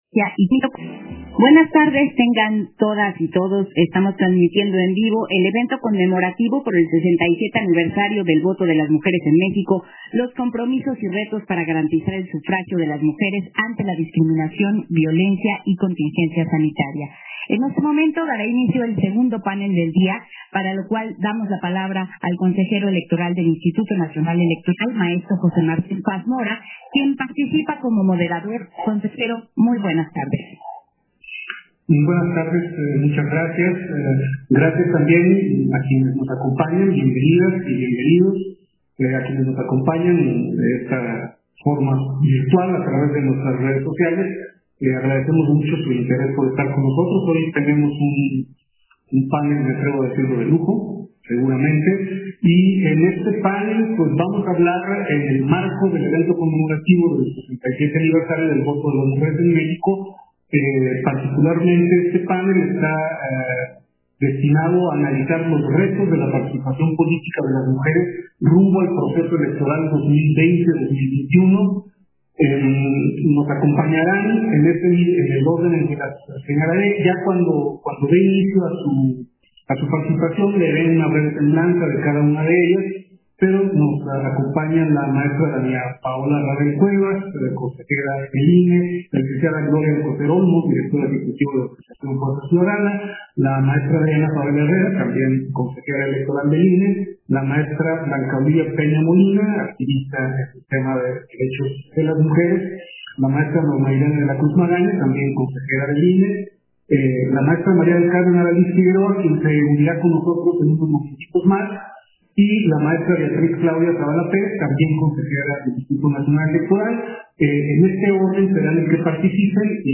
191020_AUDIO_PANEL_LOS-RETOS-DE-LA-PARTICIPACIÓN-POLÍTICA